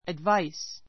ədváis